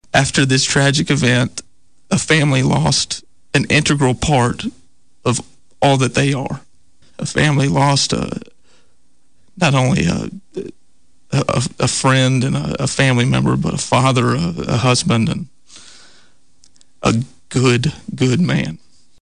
Weakley County Mayor Dale Hutcherson spoke with Thunderbolt news about the loss of a beloved man.